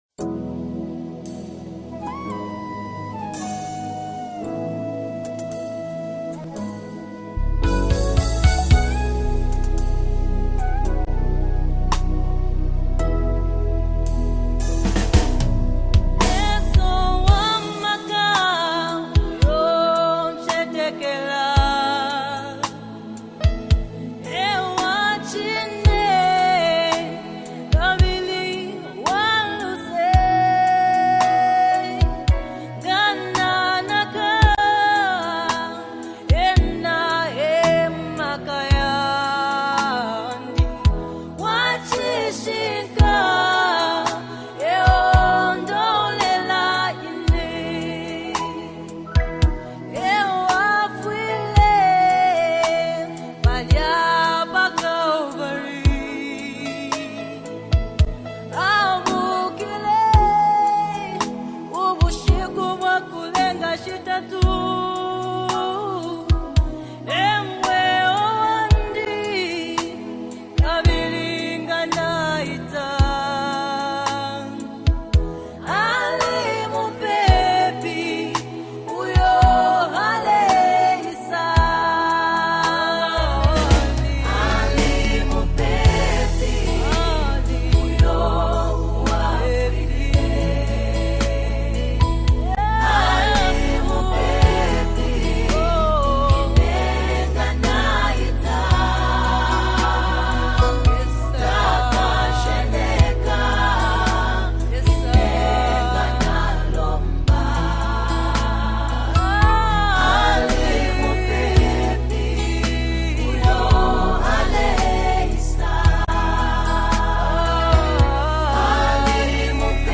Gospel Music
has once again graced us with another soulful track
deep voice and emotive lyrics